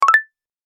Index of /phonetones/unzipped/Alcatel/OT-3088X/notifications
notifier_Microtri.ogg